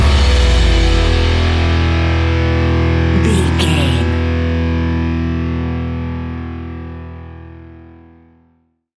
Ionian/Major
guitars
hard rock
heavy rock
blues rock
distortion
punk metal